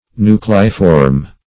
nucleiform - definition of nucleiform - synonyms, pronunciation, spelling from Free Dictionary
Search Result for " nucleiform" : The Collaborative International Dictionary of English v.0.48: Nucleiform \Nu*cle"i*form\, a. [L. nucleus kernel + -form.]